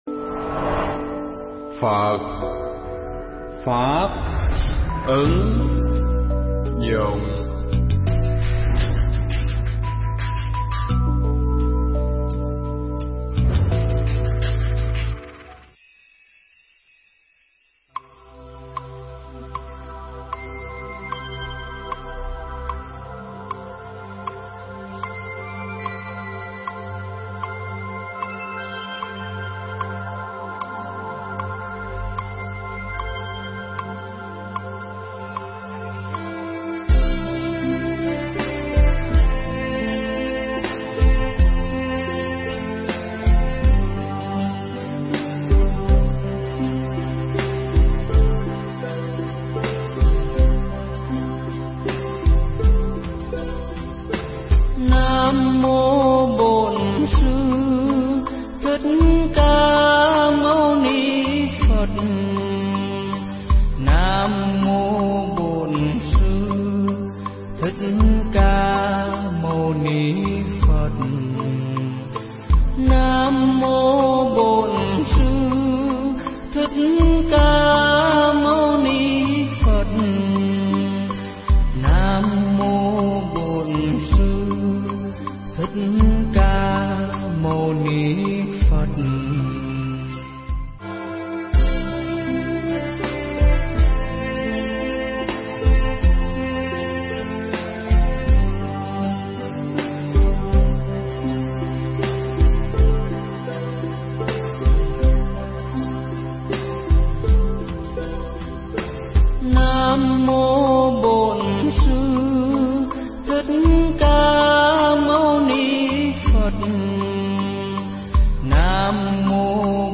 Mp3 Thuyết Pháp Ý Nghĩa Chữ Vạn Và Những Tướng Tốt Của Đức Phật
giảng nhân ngày đại lễ Phật đản lần thứ 2640 tại Tu Viện Tường Vân